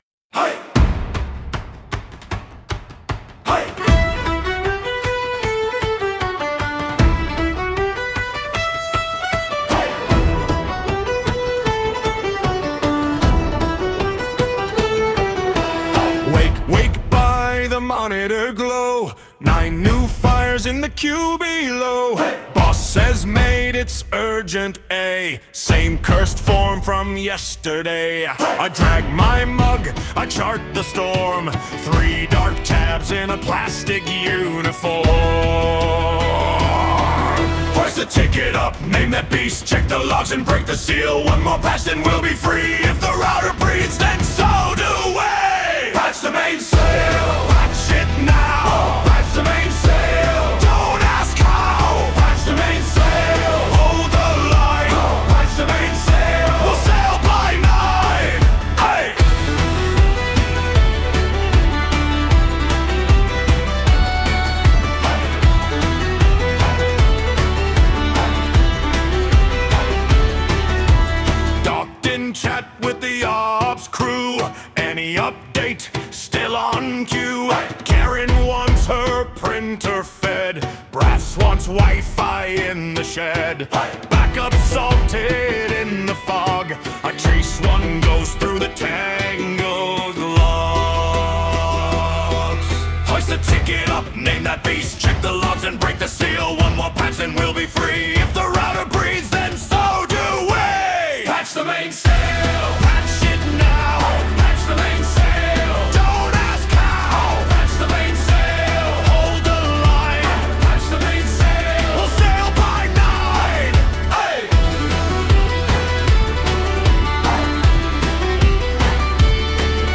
In the “Styles” box, I listed the genre and instruments: “Pirate Shanty, Sea Shanty, Male Vocals, Accordion, Stomps, Fiddle, 90 BPM.”
I toggled “Vocal Gender” to Male to keep it consistent with a traditional shanty crew and hit “Create.”
Neither of them had quite the distinct stomps and claps at the beginning I was looking for, but it was close enough. But there was a fiddle solo in the middle of both of them as requested.
By using structural tags in “Advanced” mode, I forced a break in the music, where the fiddle solo took center stage.